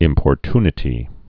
(ĭmpôr-tnĭ-tē, -ty-)